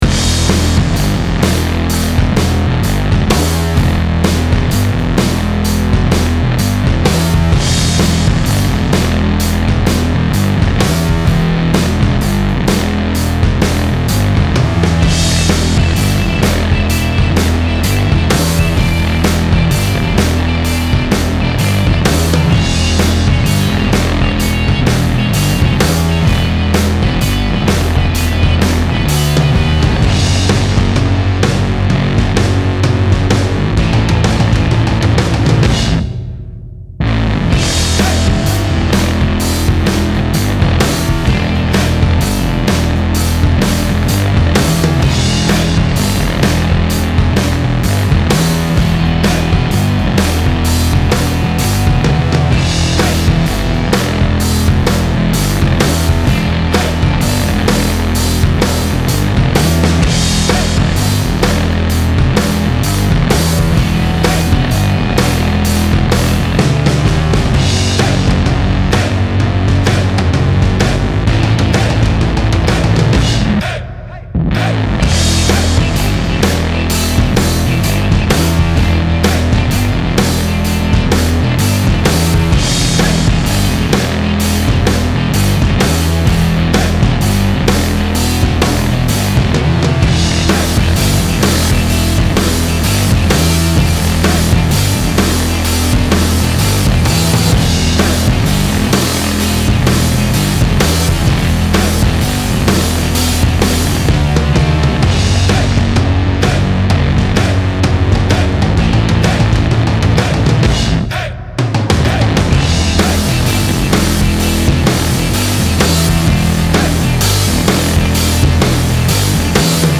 Style Style Rock
Mood Mood Aggressive, Driving
Featured Featured Bass, Drums, Electric Guitar
BPM BPM 128